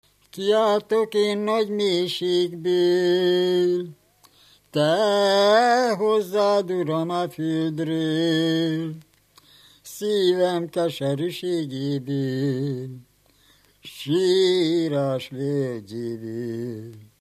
Moldva és Bukovina - Bukovina - Istensegíts
ének
Stílus: 4. Sirató stílusú dallamok